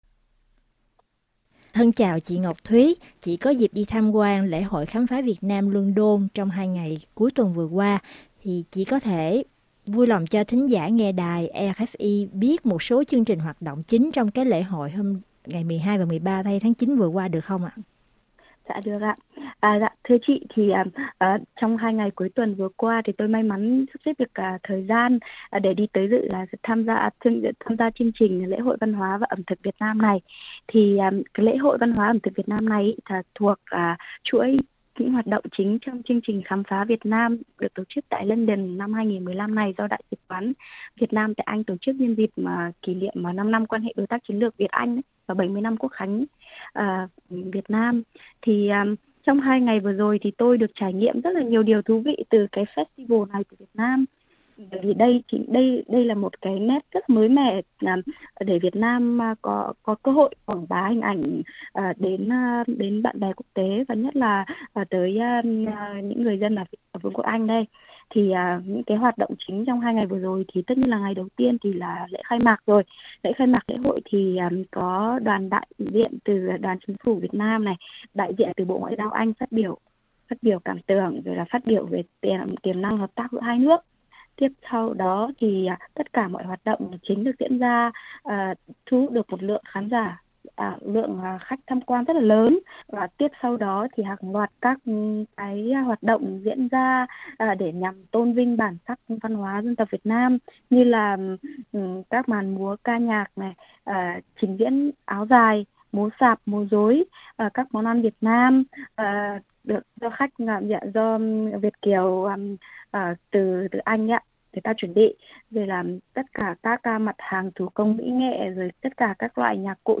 Luân Đôn